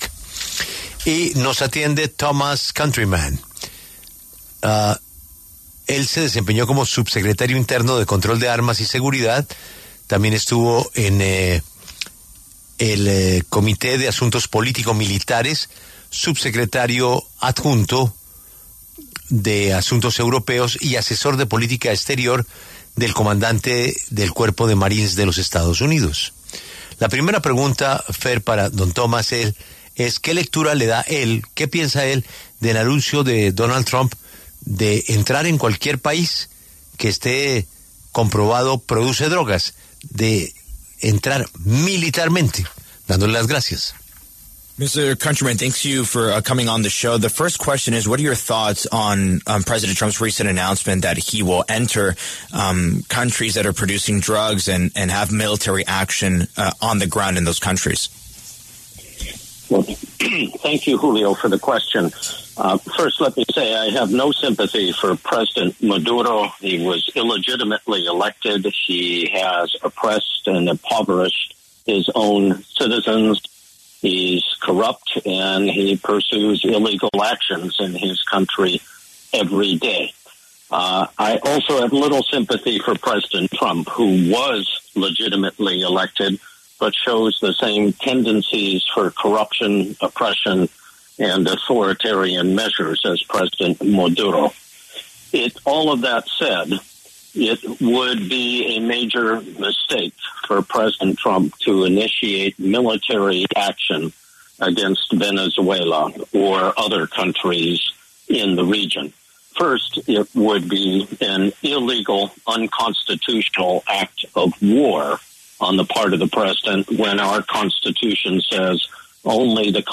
Thomas Countryman, quien se desempeñó como subsecretario interino de Control de Armas y Seguridad Internacional de Estados Unidos (2016-2017), y ocupó otros cargos en el Gobierno norteamericano, se refirió en entrevista con La W a las recientes declaraciones de Donald Trump, en las que mencionó que cualquier país que trafique droga hacia EE.UU. “está sujeto a ataques”, incluyendo allí a Colombia y Venezuela.